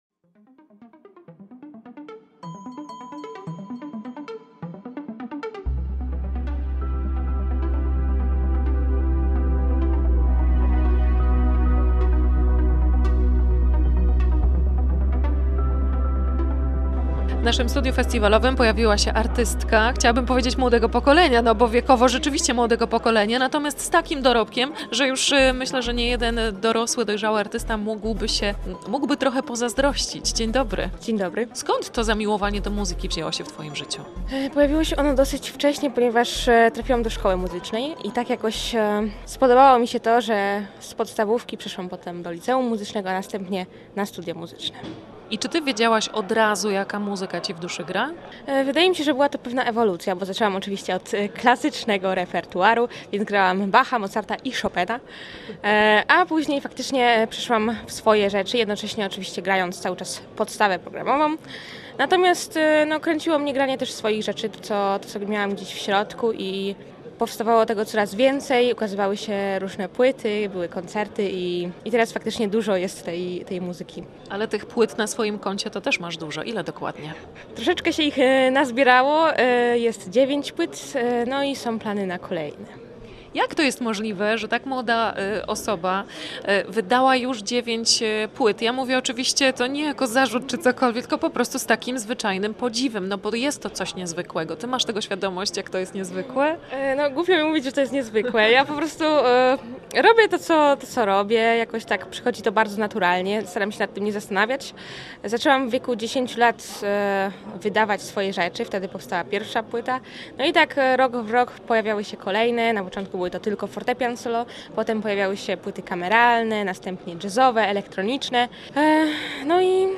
w Studiu Festiwalowym Radia Gdańsk na 50. FPFF w Gdyni